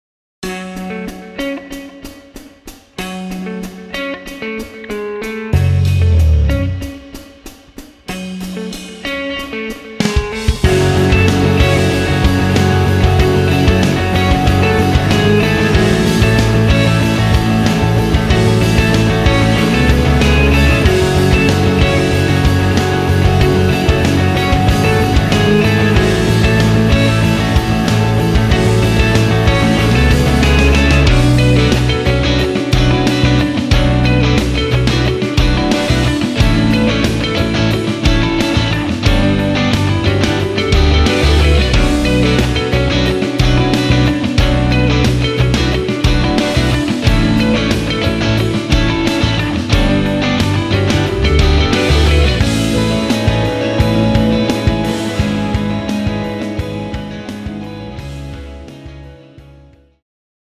Hip-Hop
guitar loop kit